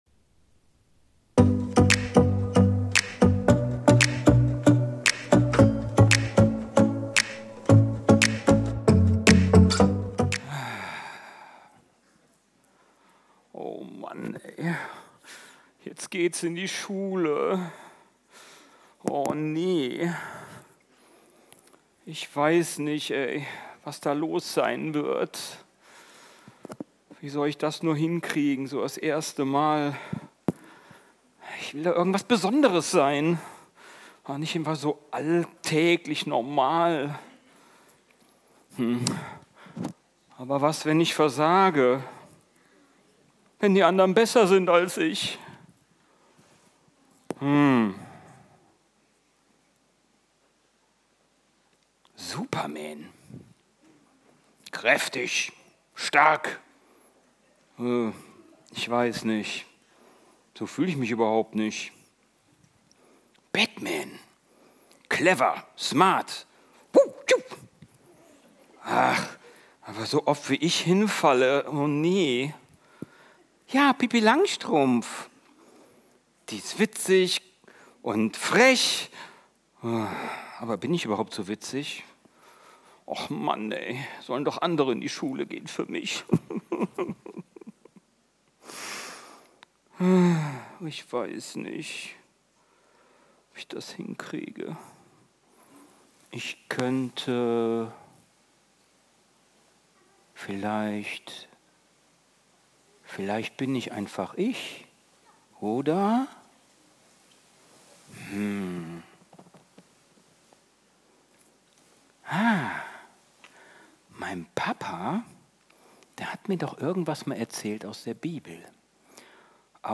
Video und MP3 Predigten
Kategorie: Sonntaggottesdienst